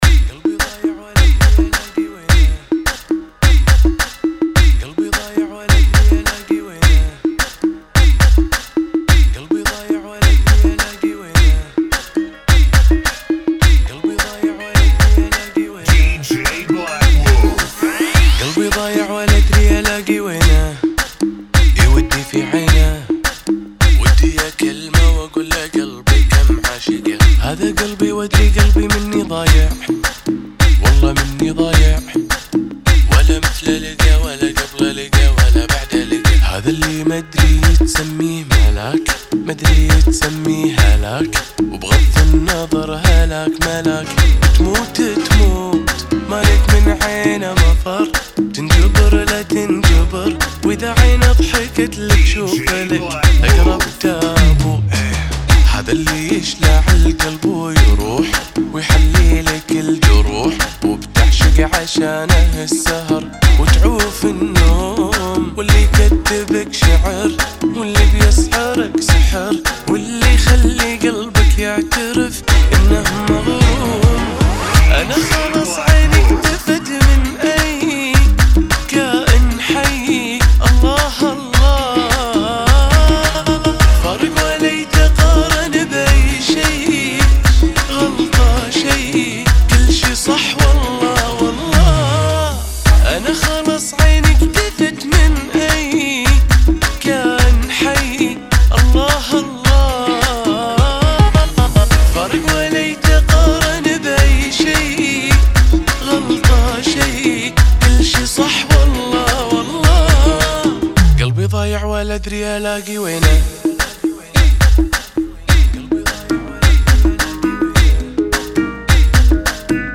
96 Bpm